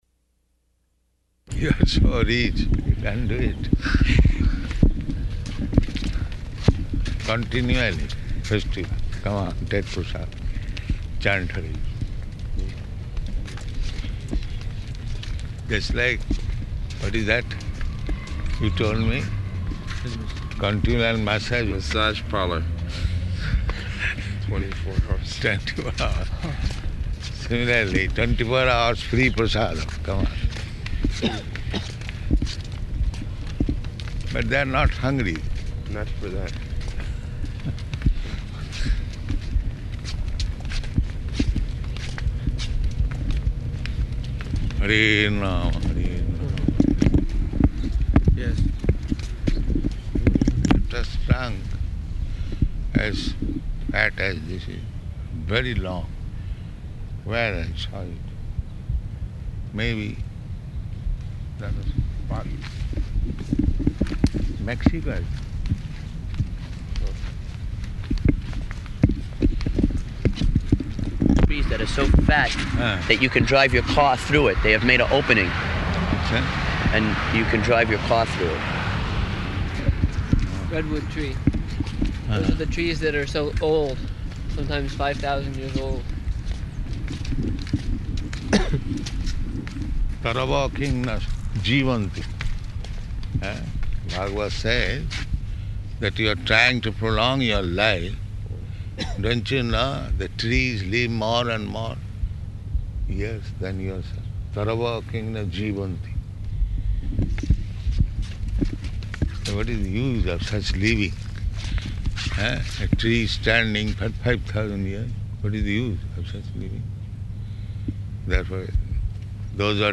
Morning Walk
Type: Walk
Location: San Diego